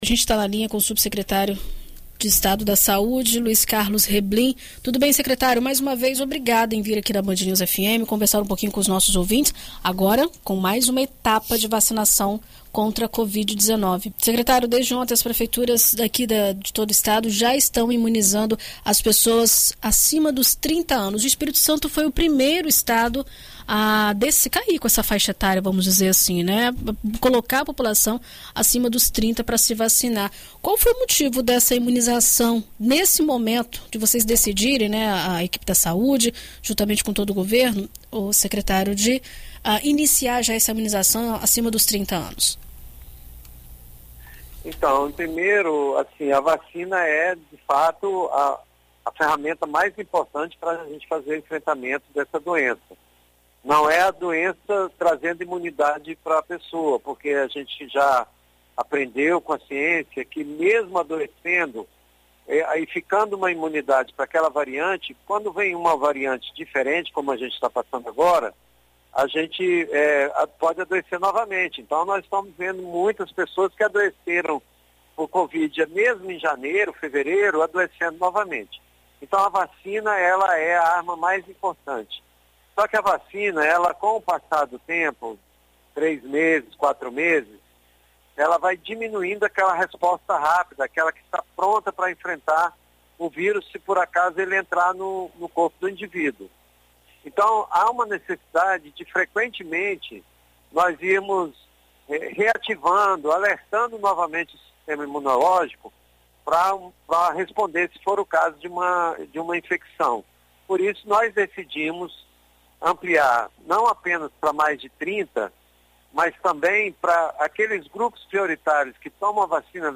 Em entrevista à BandNews FM Espírito Santo nesta terça-feira (21), o subsecretário de Estado de Vigilância em Saúde, Luiz Carlos Reblin, conversa sobre a ampliação do público e fala a respeito dos reforços para vacinados com a Janssen e as suspeitas de contaminação pelo monkeypox .